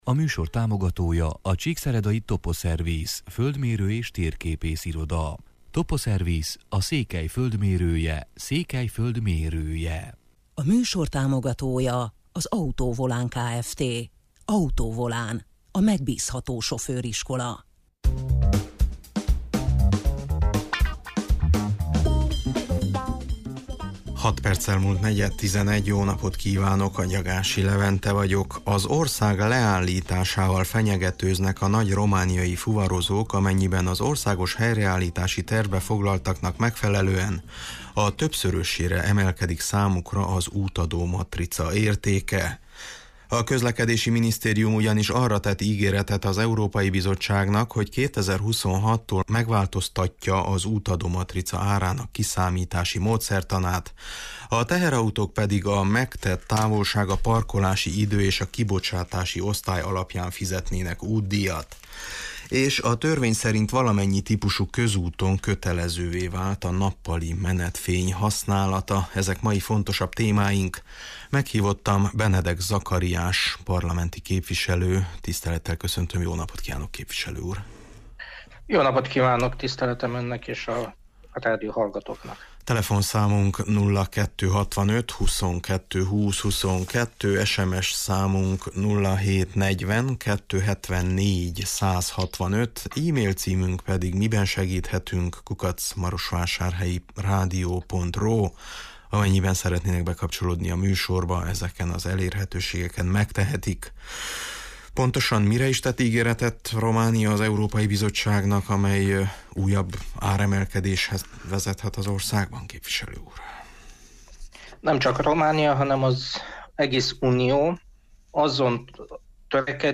Meghívottam Benedek Zakariás parlamenti képviselő: